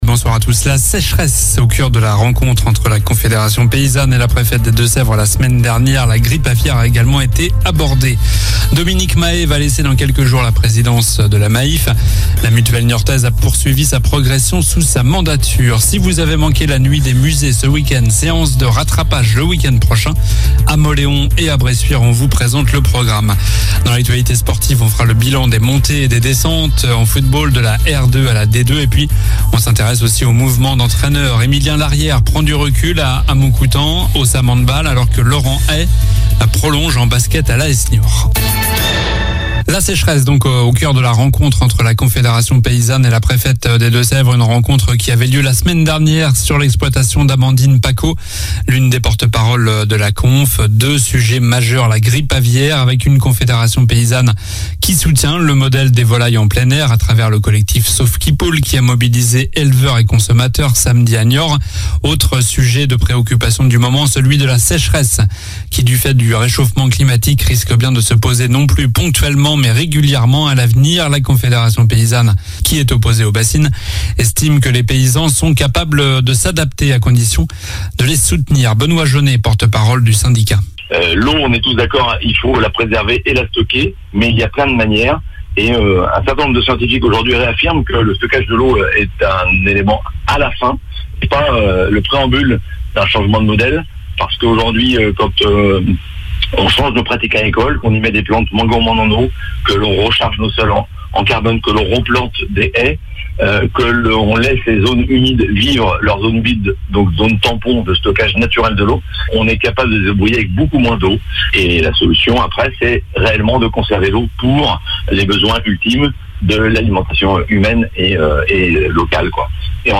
JOURNAL DU LUNDI 16 MAI ( soir )